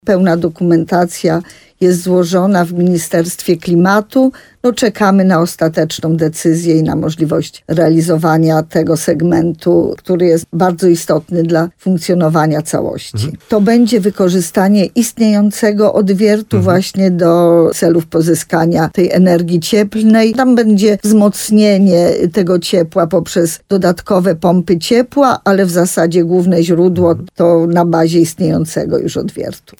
– Woda miałaby być tu ogrzewana z tzw. suchej geotermii – mówiła w programie Słowo za słowo na antenie RDN Nowy Sącz wójt gminy Sękowa Małgorzata Małuch.
Rozmowa z Małgorzatą Małuch: Wizualizacja basenów w Sękowej: geotermy_sekowa_projekt Zdjęcie 1 z 4 fot.